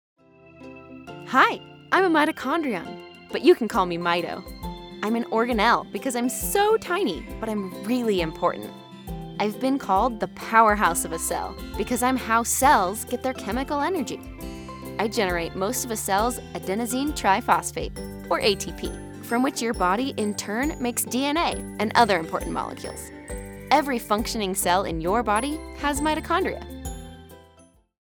Marvelous Mitochondrion - Elearning Demo
I do voiceover full time from a professionally treated in-home studio using a Sennheiser MKH 416 microphone and Apollo Twin X Duo interface.
I was born just outside Seattle Washington, which means I offer that neutral accent that can be used nation wide.
Friendly, conversational, girl next door